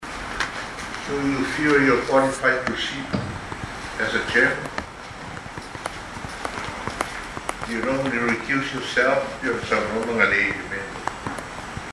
He verbally gave his resignation at a hearing of the SSIC this morning into the vehicles imported from China which have been seized by Customs.
Senate President Tuaolo Manaia Fruean put this question to Togiola.